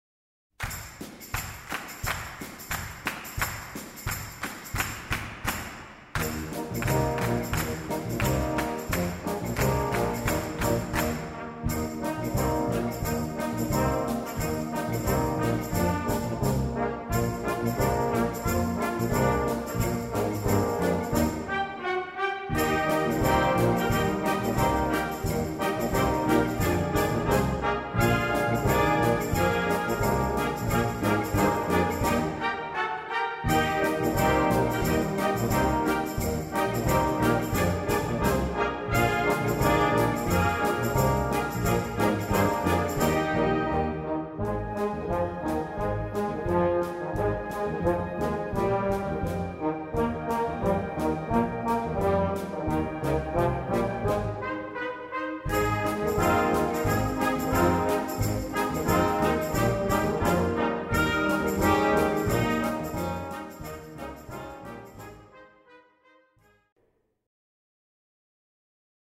Besetzung: Blasorchester
Lockere Bearbeitung einer mündlich überlieferten Melodie.